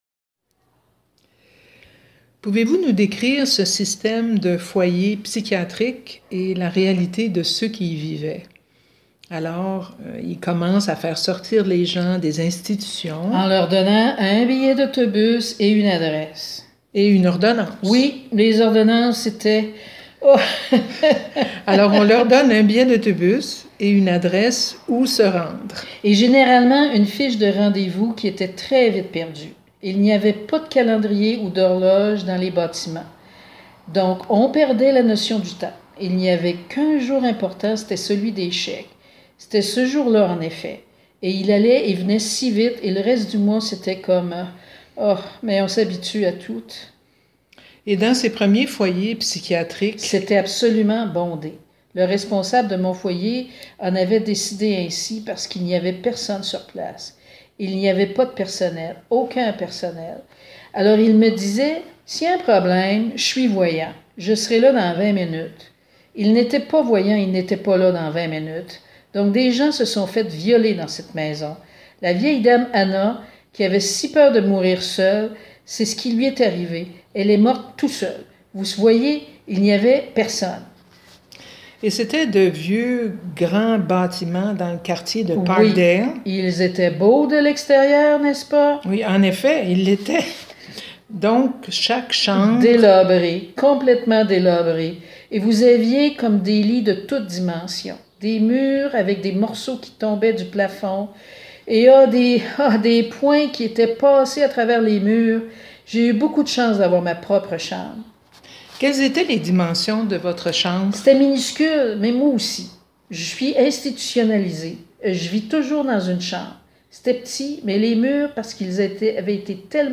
sous forme de segments d’entrevue audio et d’extraits de rédaction.